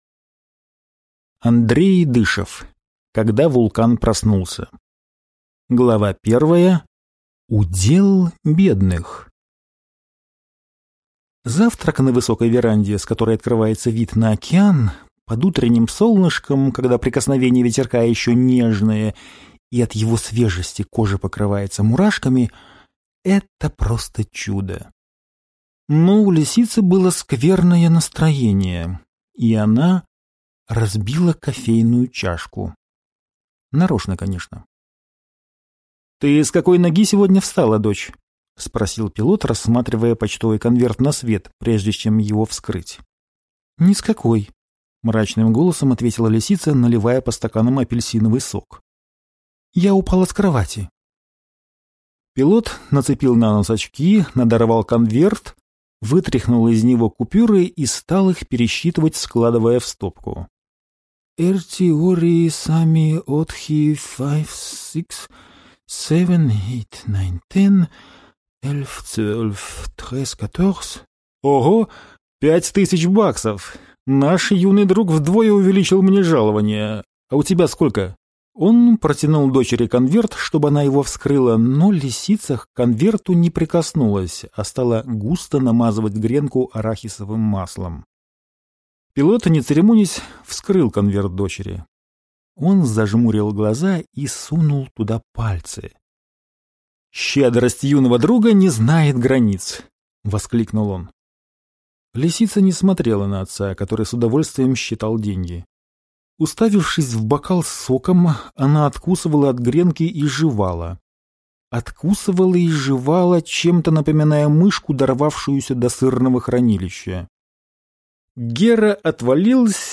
Аудиокнига Когда вулкан проснулся | Библиотека аудиокниг